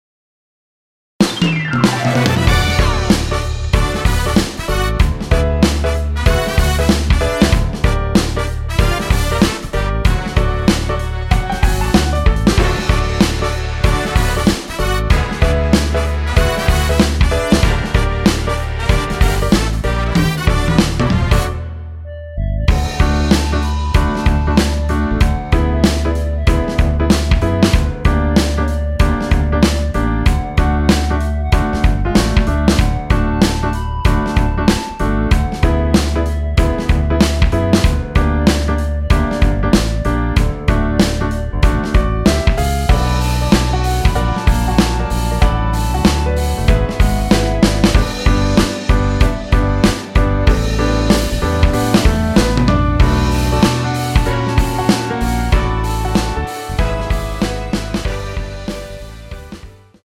원키 멜로디 포함된 MR입니다.(미리듣기 확인)
D
멜로디 MR이라고 합니다.
앞부분30초, 뒷부분30초씩 편집해서 올려 드리고 있습니다.
중간에 음이 끈어지고 다시 나오는 이유는